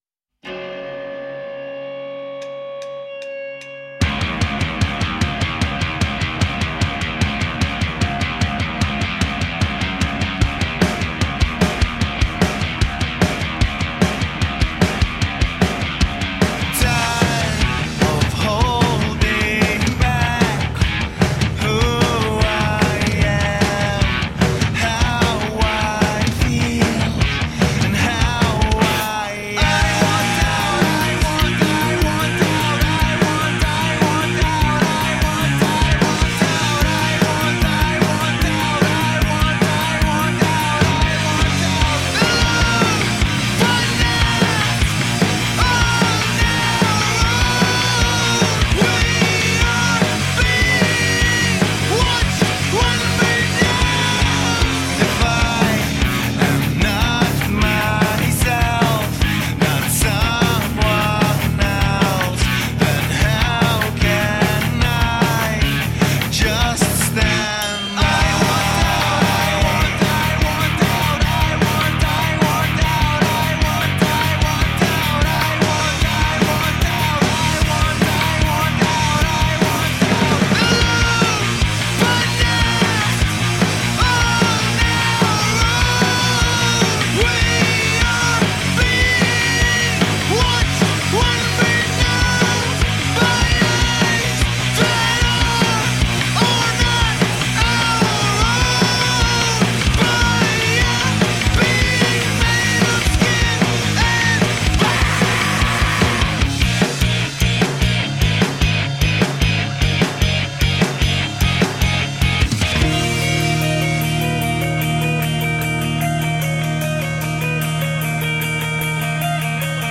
Cunning & ferocious rock music.
Tagged as: Hard Rock, Metal